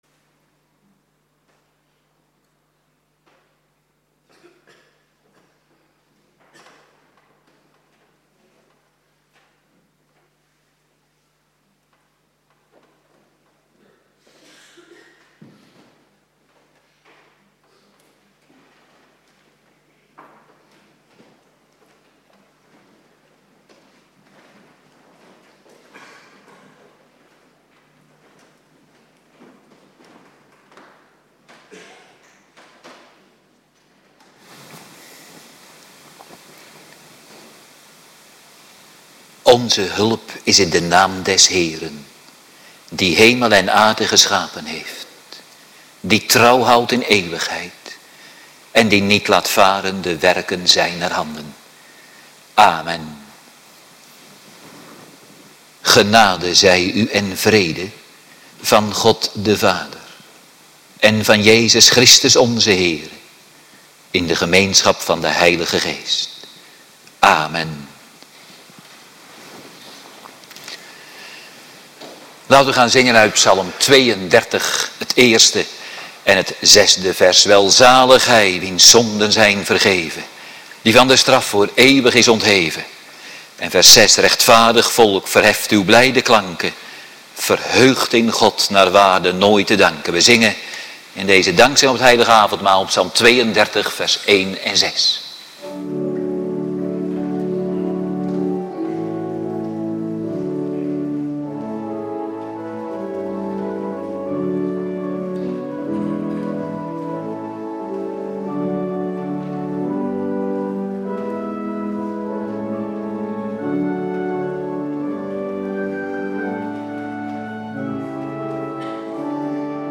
Avonddienst Nabetrachting Heilig Avondmaal
Locatie: Hervormde Gemeente Waarder